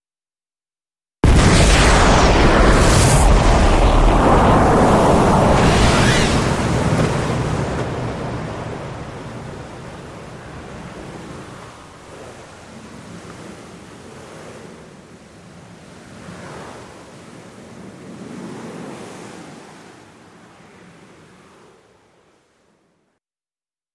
Depth Charge
Explosion, Surface Perspective Depth Charge Multiple Ver. 2